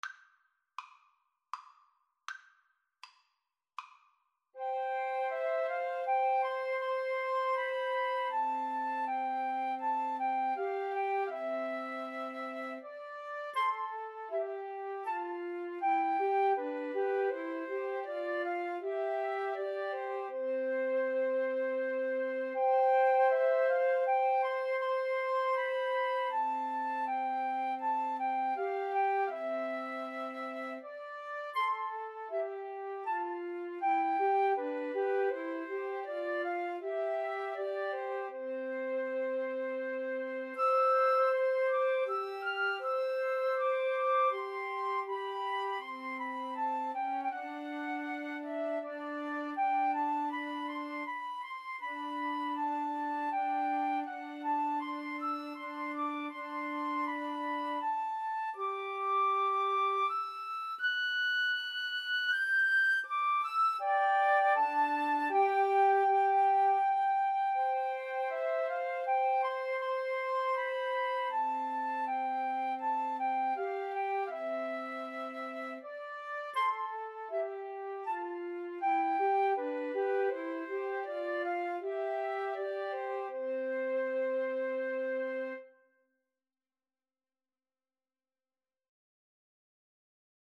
Free Sheet music for Flute Trio
C major (Sounding Pitch) (View more C major Music for Flute Trio )
Andante